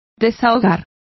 Complete with pronunciation of the translation of unburden.